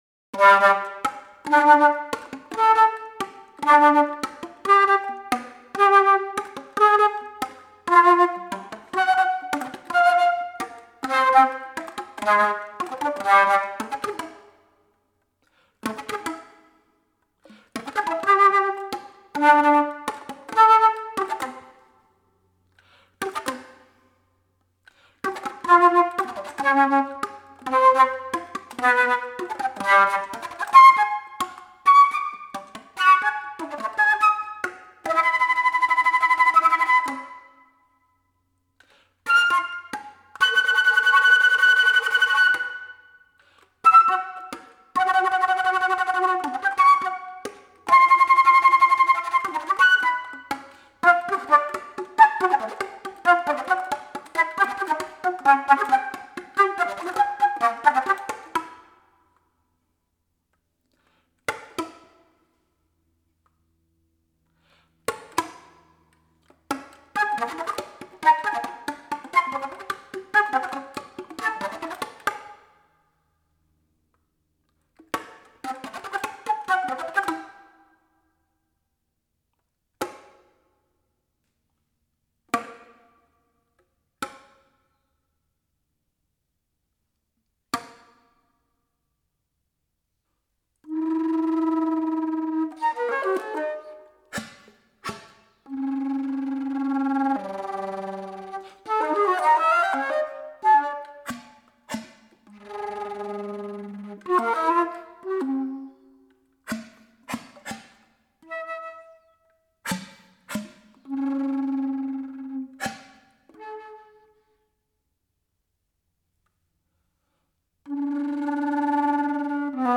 Golpes para flauta